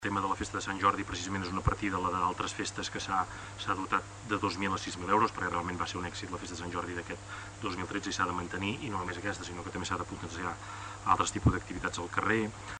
Durant el ple, l'alcalde,